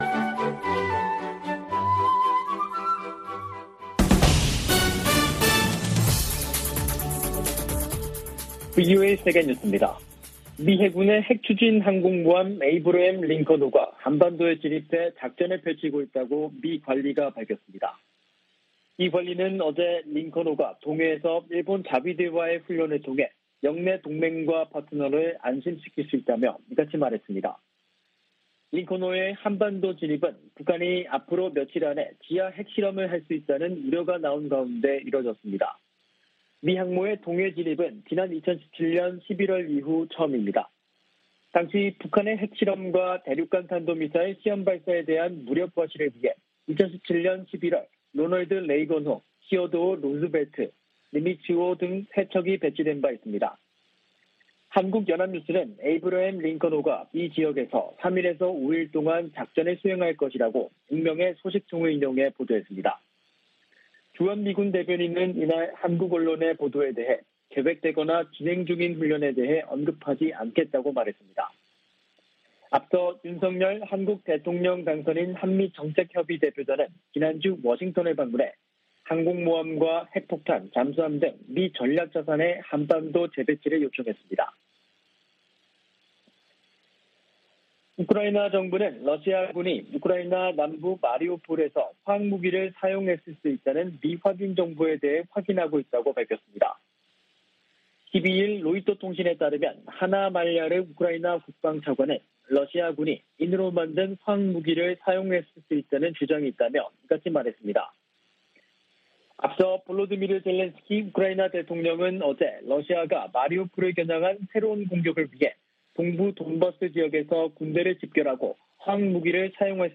VOA 한국어 간판 뉴스 프로그램 '뉴스 투데이', 2022년 4월 12일 2부 방송입니다. 미국의 핵 추진 항공모함 에이브러햄 링컨함이 한반도 동해 공해상에 전개됐습니다.